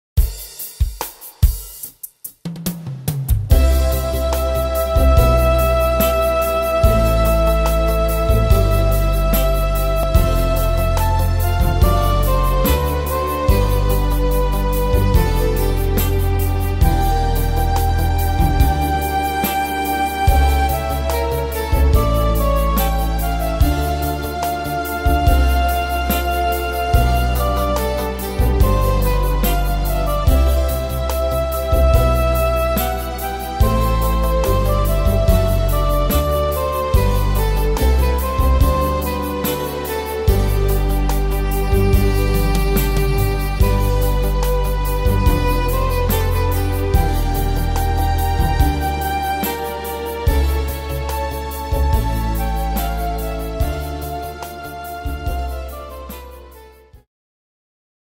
Tempo: 72 / Tonart: C-Dur